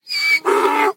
sounds / mob / horse / donkey / angry2.mp3